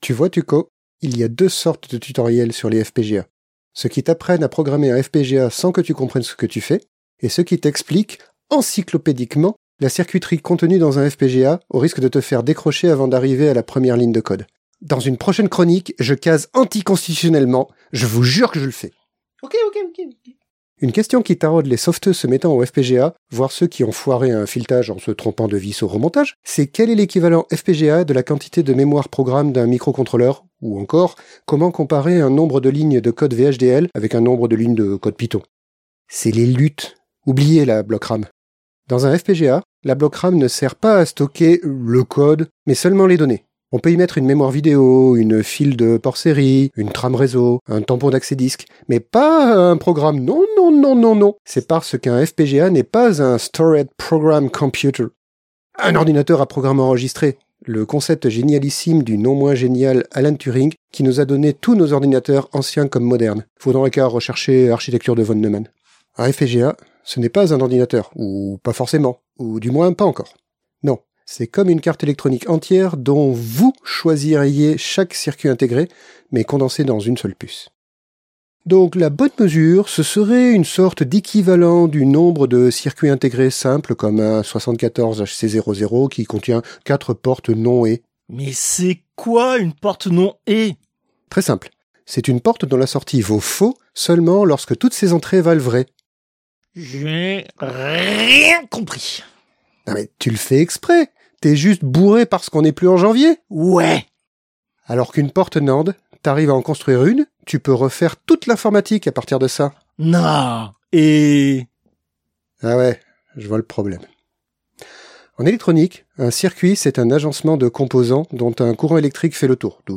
Extrait de l'émission CPU release Ex0233 : FPGA, deuxième partie.